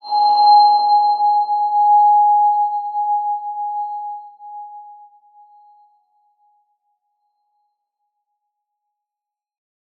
X_BasicBells-G#3-pp.wav